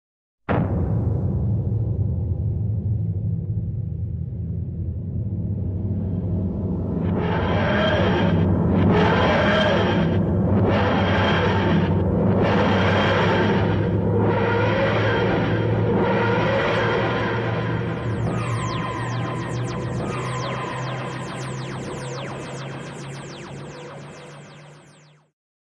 BBC 드라마 닥터 후에 등장하는 타임머신 타디스가 사라질 때 내는 효과
TARDIS_sound_effect.ogg.mp3